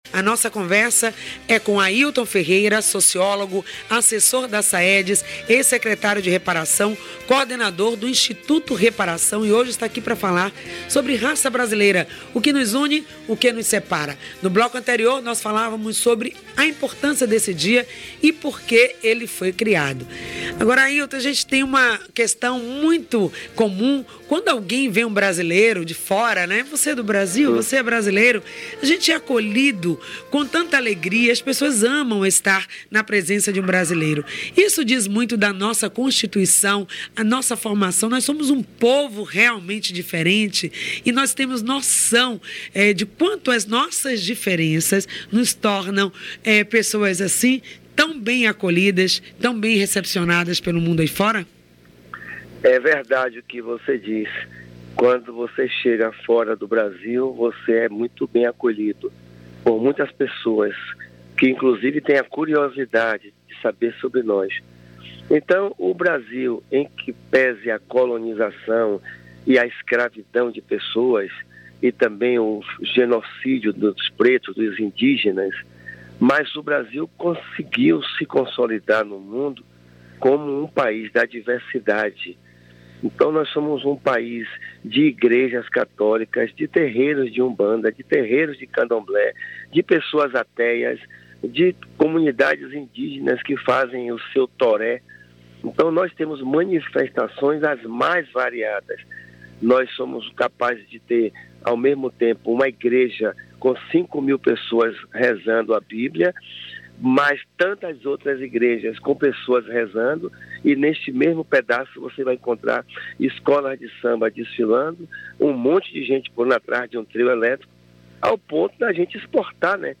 Sobre o tema: Raça Brasileira o que nos une e o que nos separa . Ouça a entrevista: https
O Programa Em Sintonia, é transmitido pela FM 106.1 das 8 às 10 da manhã de segunda à sexta.